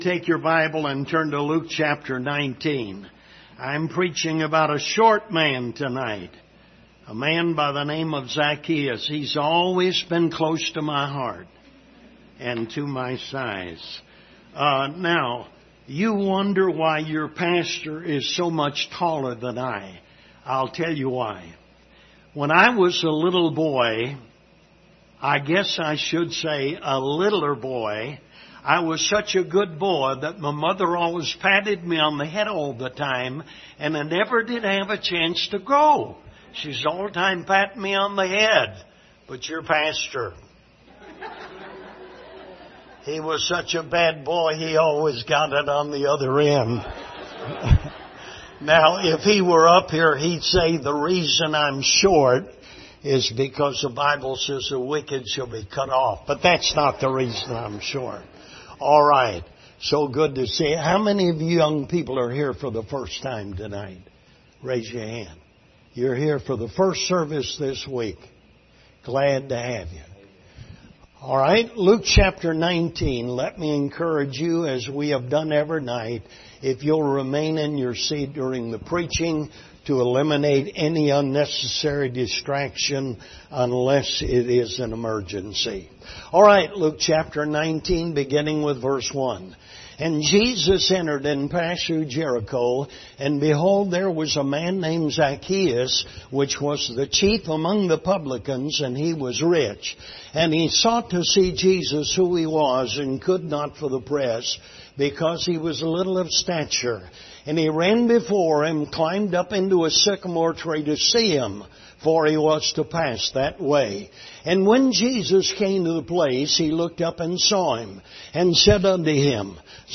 Passage: Luke 19:1-10 Service Type: Revival Service Topics